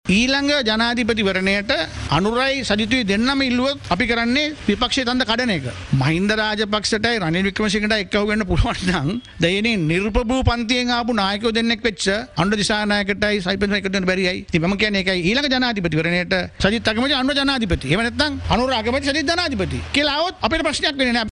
කොළොඹ පැවති  මාධ්‍ය හමුවකට එක්වෙමින් ඒ  මහතා මෙම දහස් දැක්වීම සිදු කළා .